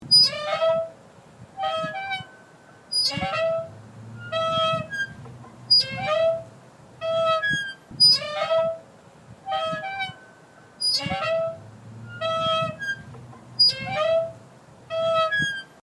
Tiếng ngồi Xích Đu đung đưa kêu Cót Két
Thể loại: Tiếng đồ vật
Description: Đây là âm thanh đặc trưng khi một chiếc xích đu cũ được đưa qua lại, tạo nên tiếng kim loại hoặc gỗ ma sát kêu rít rít, lạch cạch, kẽo kẹt, kẹt kẹt, hoặc cọt kẹt... vang vọng trong không gian yên tĩnh.
tieng-ngoi-xich-du-dung-dua-keu-cot-ket-www_tiengdong_com.mp3